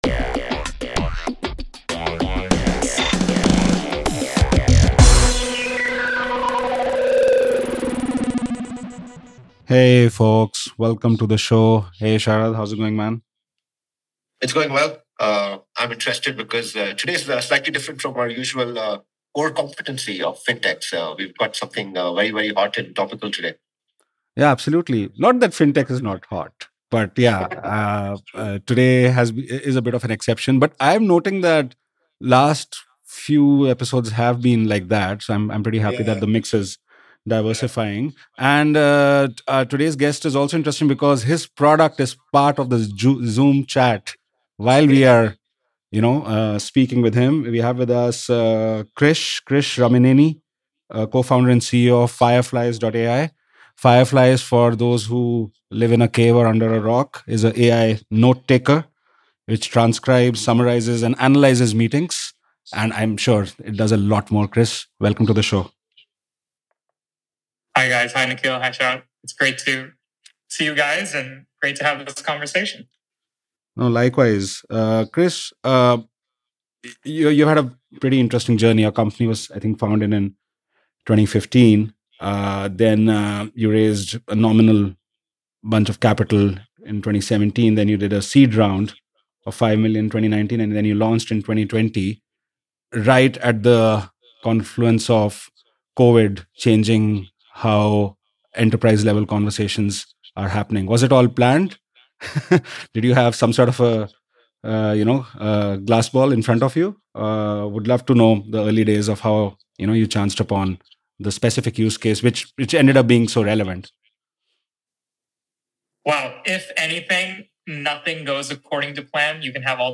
where we converse with entrepreneurs and business operators running successful startups, profitable SMEs and family promoted firms on one end, and top investment professionals representing VC/PE/credit funds on the other.